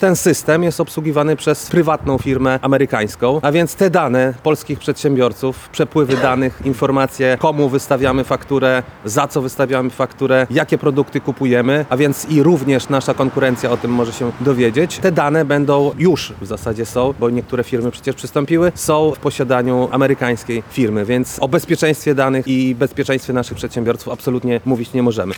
W tej sprawie poseł Pejo zorganizował konferencję w Lublinie z udziałem przedstawicieli Ruchu Narodowego oraz przedsiębiorców.